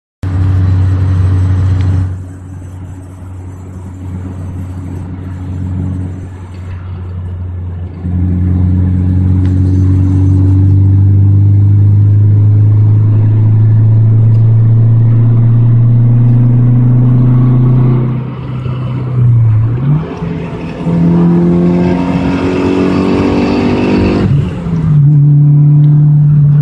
Nene das ist ein rasseln/klirren wie wennde schrauben in ner blechdose schuettelst
warm klirren.mp3
Warmer motor 5ter gang dann 3ter